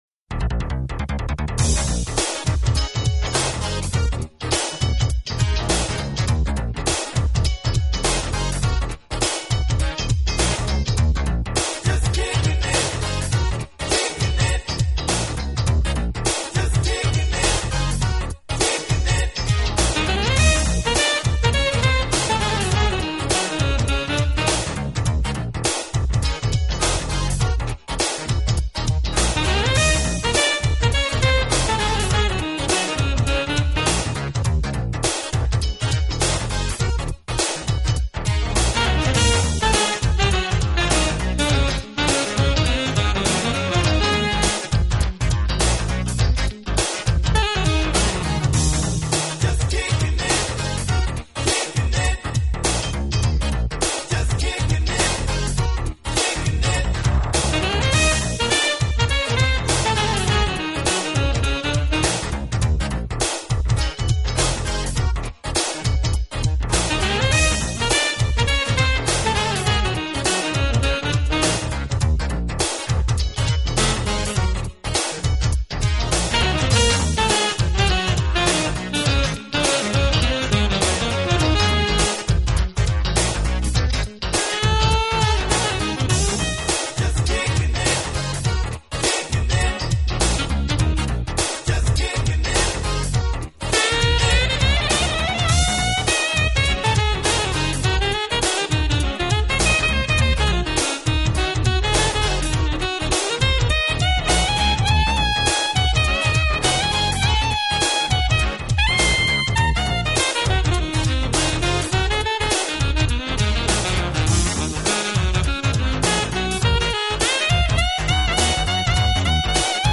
【爵士萨克斯】